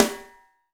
SNARE 040.wav